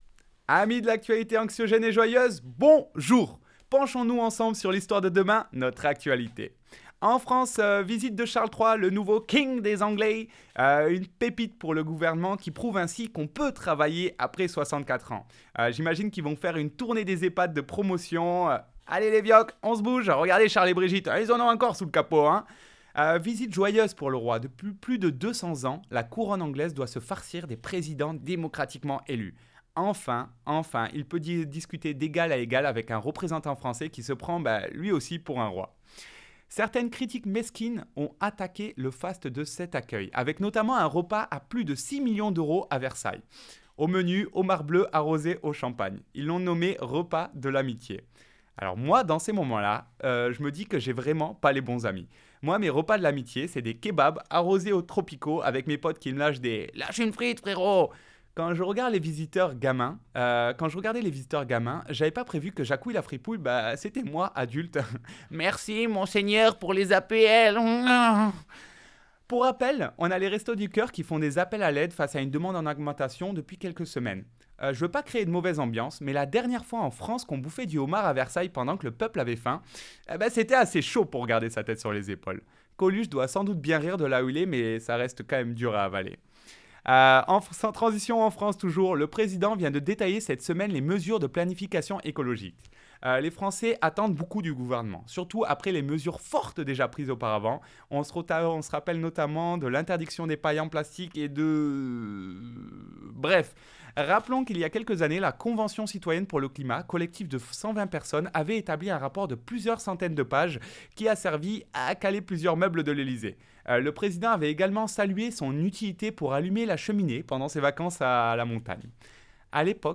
chronique humoristique